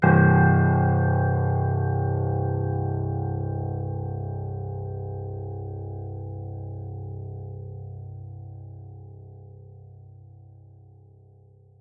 piano-sounds-dev
c0.mp3